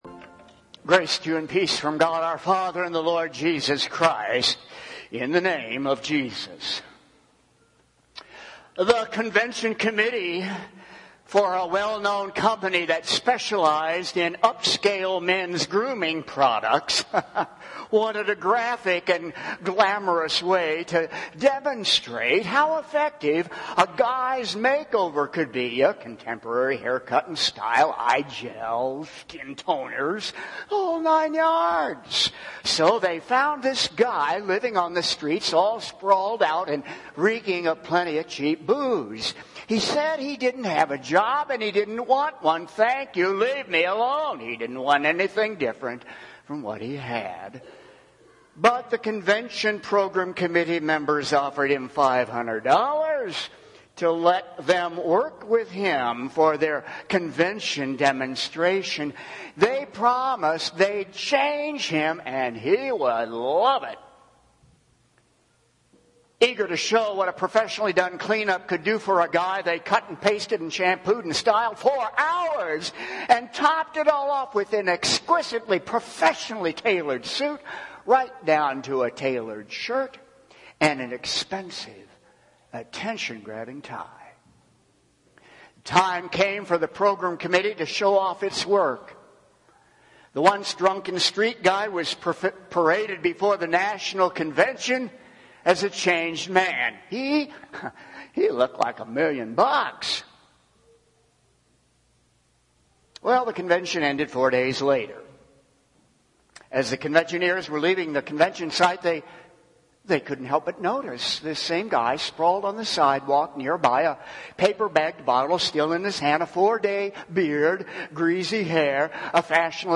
Home › Sermons › 4th Sunday after Pentecost